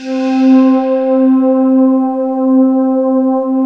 Index of /90_sSampleCDs/USB Soundscan vol.28 - Choir Acoustic & Synth [AKAI] 1CD/Partition C/08-FANTASY